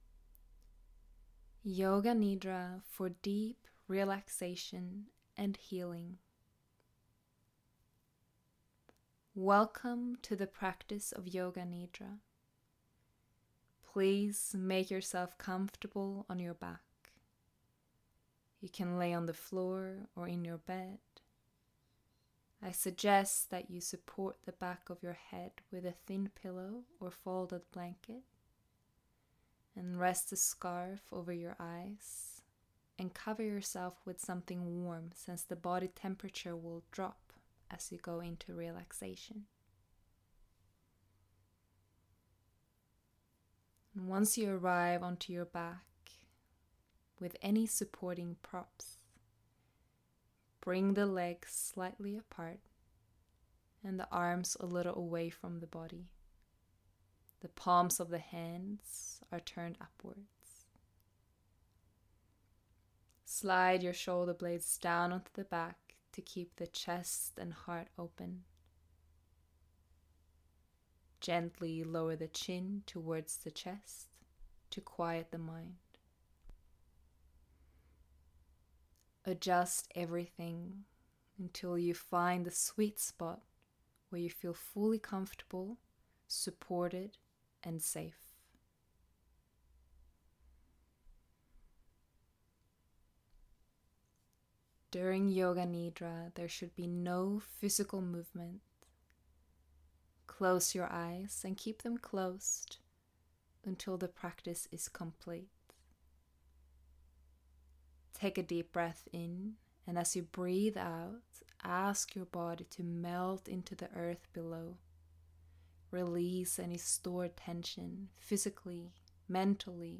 free meditation
free meditation Enjoy this Yoga Nidra practice for deep relaxation and healing. Yoga Nidra is also known as Yogic sleep, it is a nurturing practice that takes you from doing-mode into being-mode. Get comfy and cozy on your back and simply follow the voice.
Yoga-nidra-for-deep-relaxation-and-healing.mp3